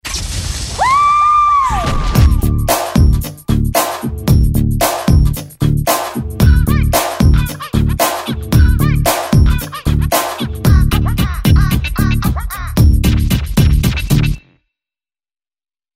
Andante [90-100] amour - batterie - jackson - danse - cri